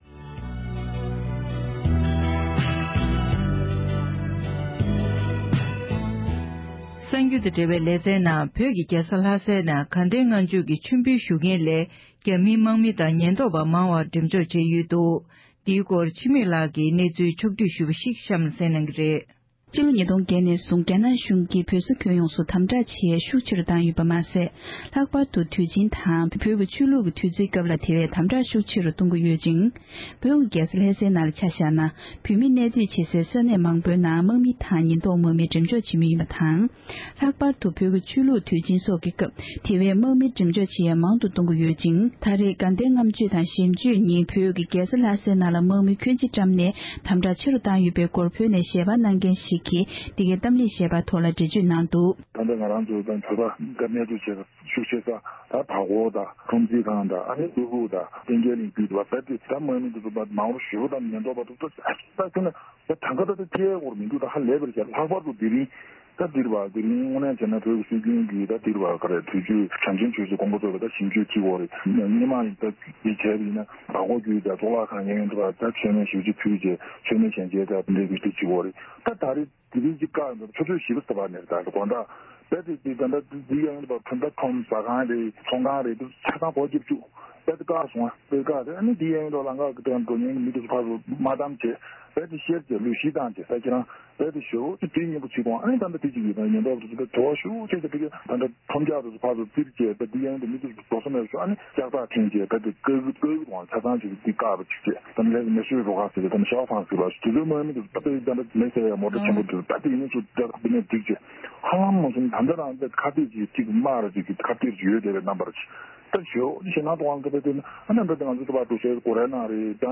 སྒྲ་ལྡན་གསར་འགྱུར། སྒྲ་ཕབ་ལེན།
༄༅༎བོད་ཀྱི་རྒྱལ་ས་ལྷ་སའི་ནང་དགའ་ལྡན་ལྔ་མཆོད་ཀྱི་མཆོད་འབུལ་ཞུ་མཁན་ལས་རྒྱ་ནག་གི་དམག་མི་དང་ཉེན་རྟོག་པ་མང་བ་ཡོད་པའི་སྐོར། བོད་ནས་བོད་མི་ཞིག་གིས་འདི་ག་ཨེ་ཤེ་ཡ་རང་དབང་རླུང་འཕྲིན་ཁང་ལ་ངོ་སྤྲོད་གནང་བར་གསན་རོགས་ཞུ༎